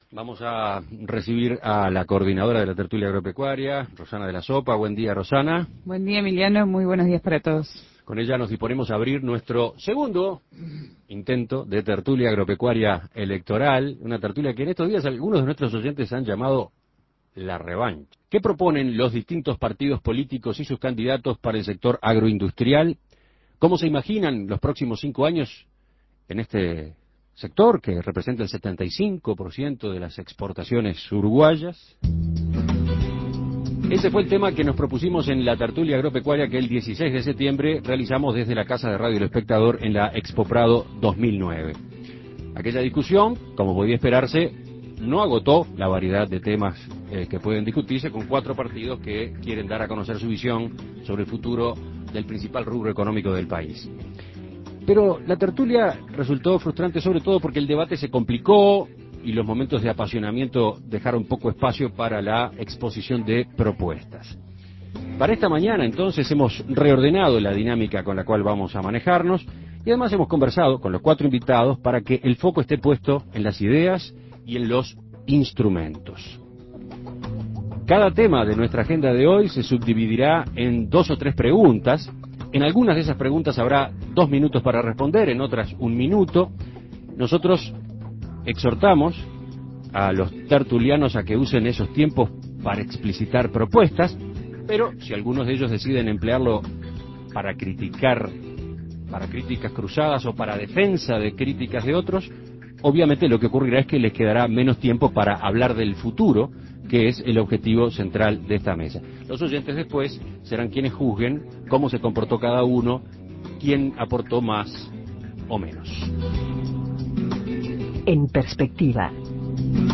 En Uruguay, las exportaciones provenientes de la agroindustria representan entre el 70 y el 75% del total, lo que lo convierte en el motor de la economía del país. Por eso, y en el marco de la campaña electoral, la Tertulia Agropecuaria reunió por segunda vez a los asesores de los presidenciables para saber cuáles son sus planes en esta materia y buscar posibles acuerdos.